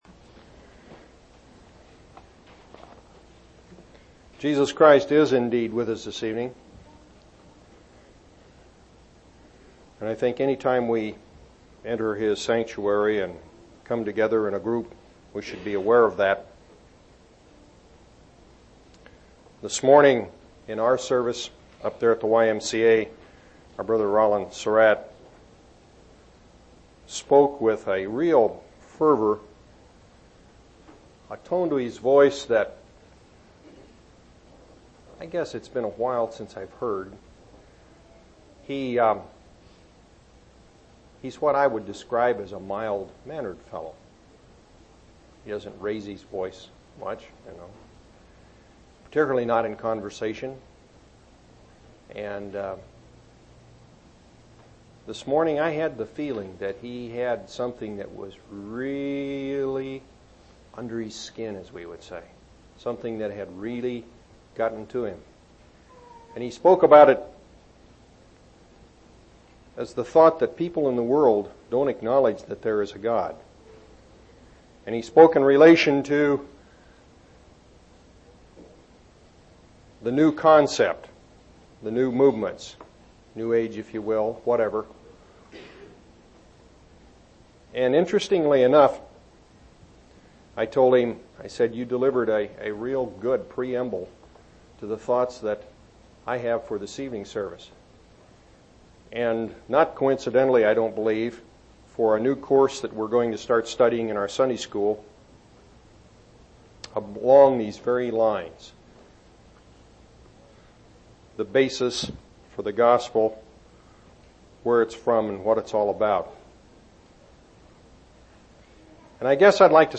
5/20/1990 Location: Temple Lot Local Event
audio-sermons